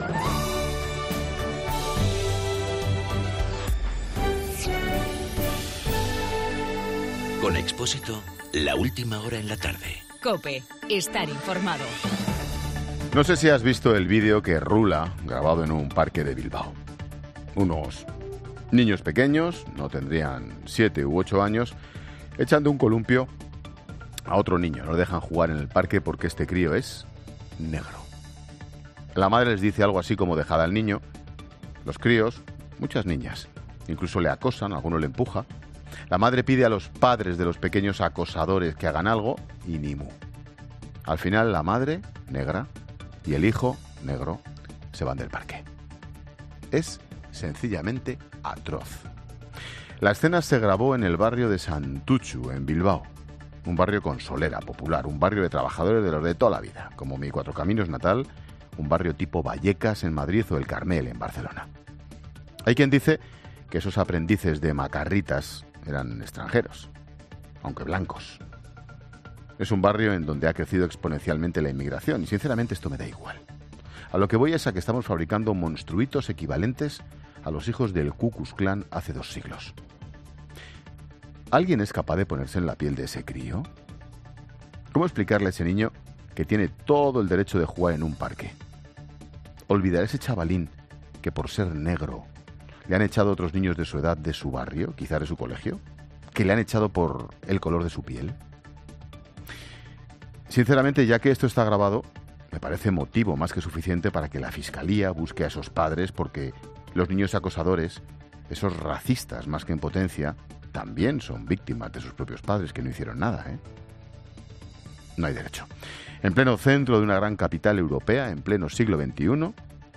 Monólogo de Expósito
El comentario de Ángel Expósito sobre un vídeo viral de una agresión de niños de 7 ú 8 años que no dejan jugar a otro en un parque infantil de Bilbao.